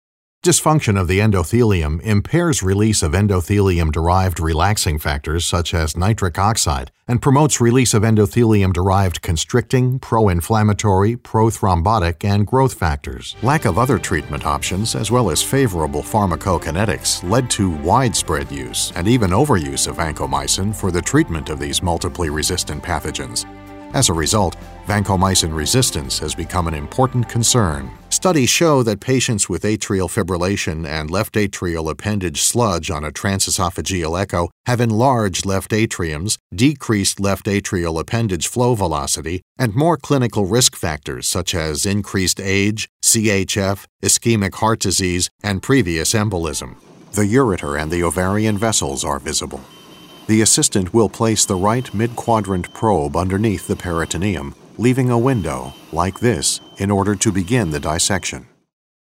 Highly experienced, credible and versatile Voice Actor/Narrator.
Sprechprobe: Sonstiges (Muttersprache):